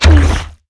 auto_alt_firev2.wav